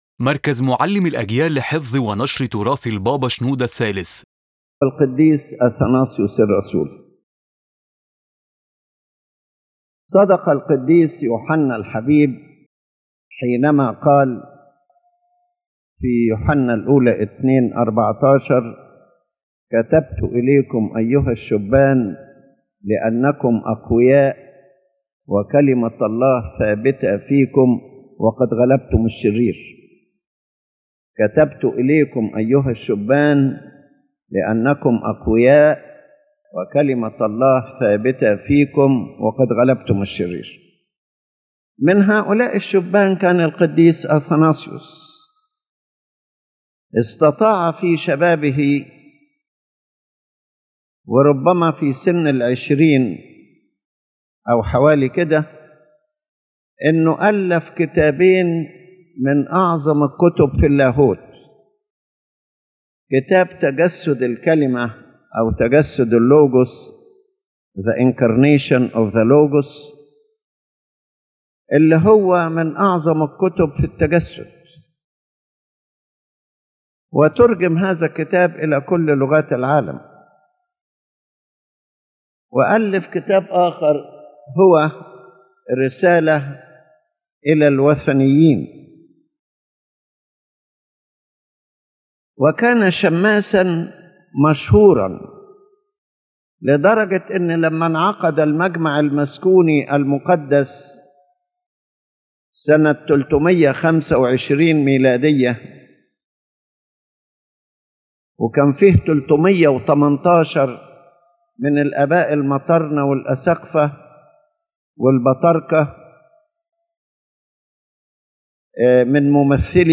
His Holiness Pope Shenouda speaks about the life and service of Saint Athanasius the Apostolic and focuses on his genius and youth in defending the Orthodox faith.